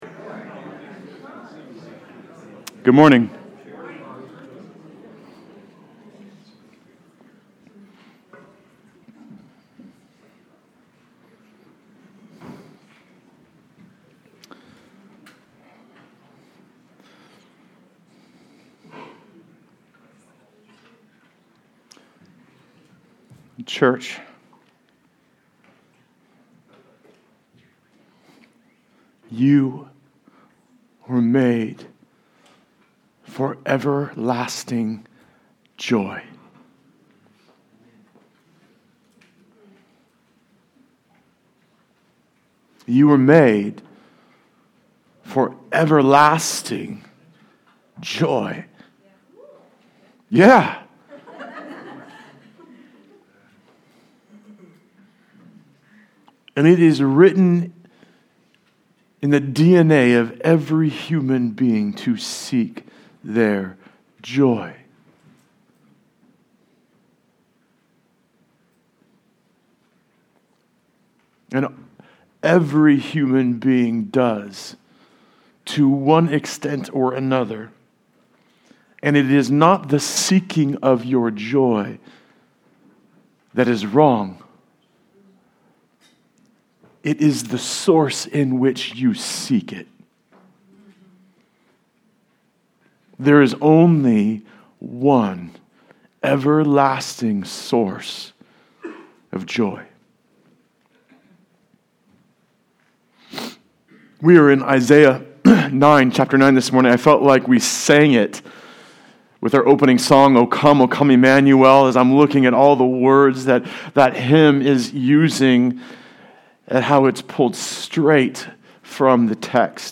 Psalm 119:121-128 Service Type: Sunday Service Related « So Much For Planning Who Do You Say I Am?